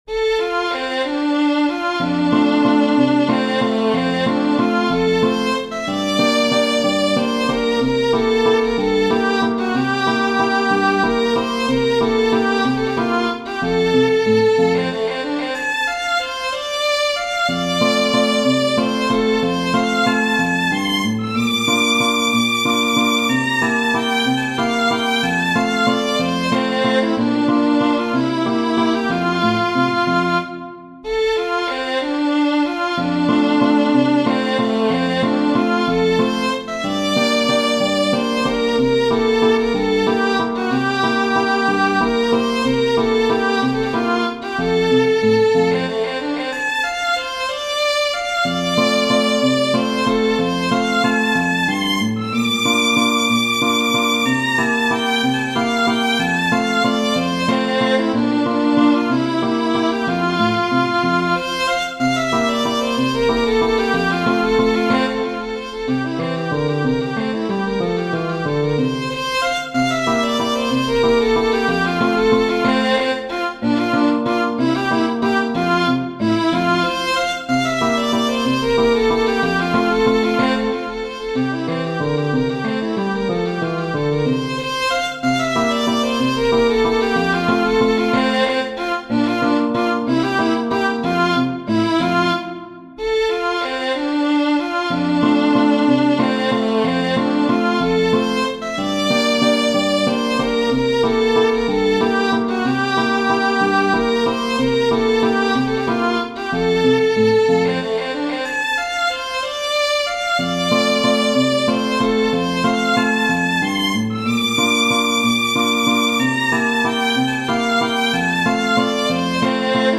Genere: Ballabili
valzer cantato